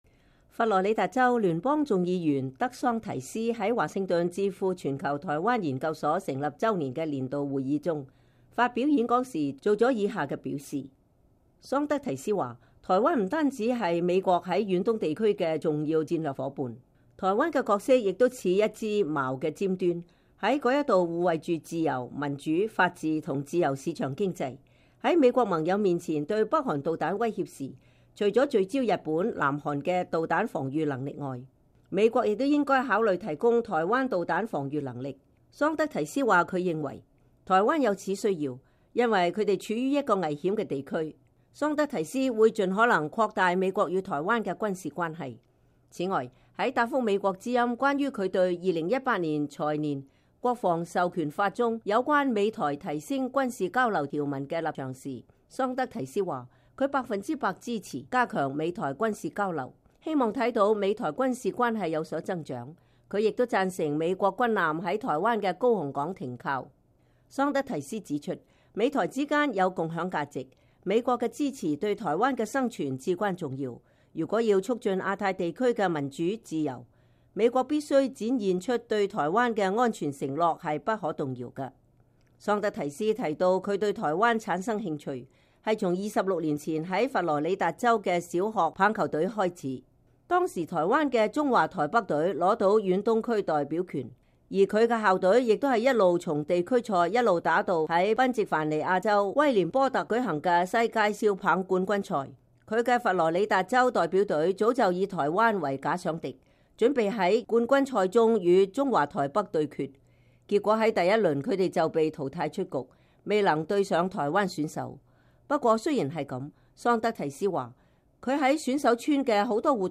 佛羅里達州聯邦眾議員德桑提斯在華盛頓智庫全球台灣研究所成立週年的年度會議中發表演說時作出上述表示。